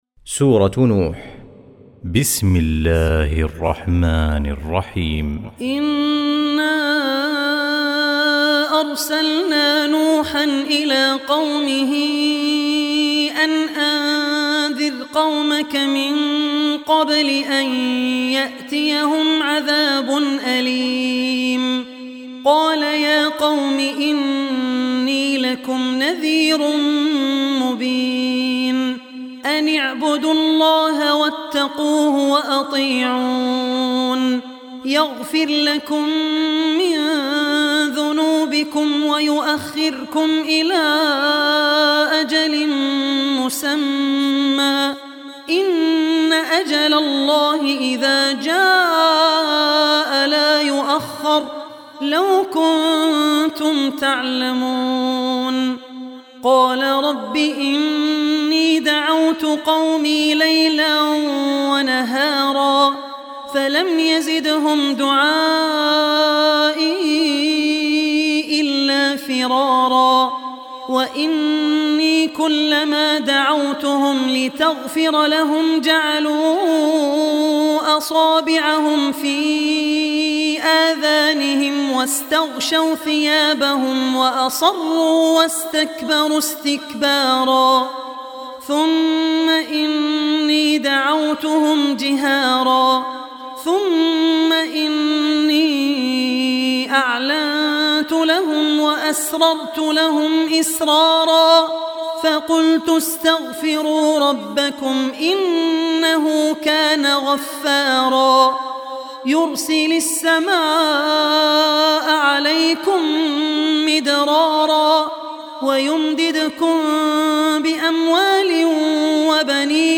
Surah Nuh Recitation